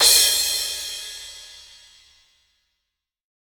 normal-hitfinish.ogg